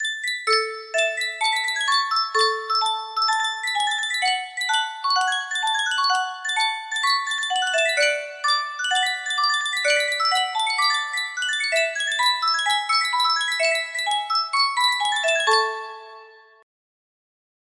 Yunsheng Music Box - Vivaldi Bajazet 5934 music box melody
Full range 60